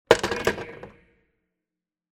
Phone receiver hang up sound effect .wav #3A
Description: The sound of hanging up a telephone receiver
Properties: 48.000 kHz 16-bit Stereo
phone-hang-up-preview-3.mp3